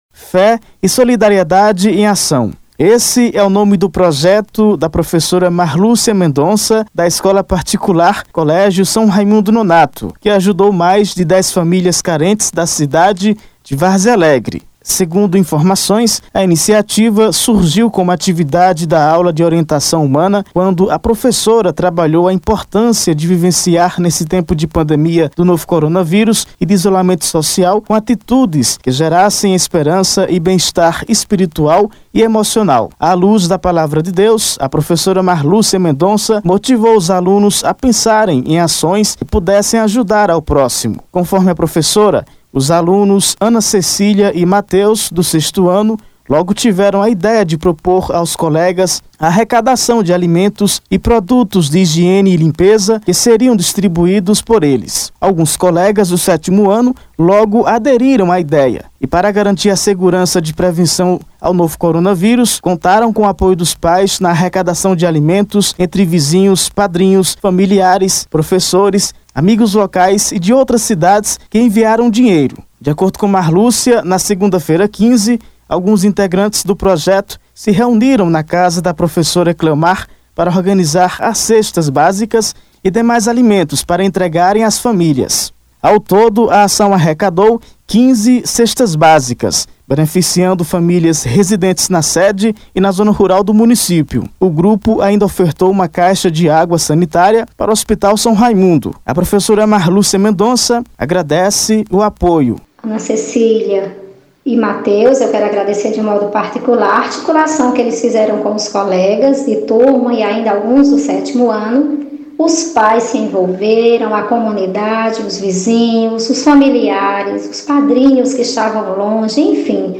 Acompanhe na reportagem completa: